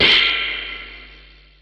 12 Harsh Realm Amp Slap.wav